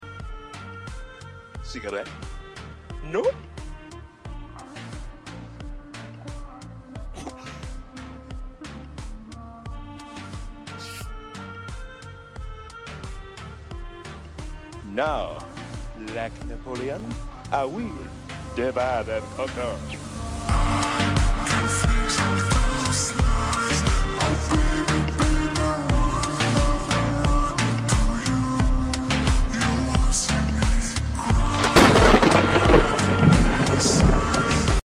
slowed+reverb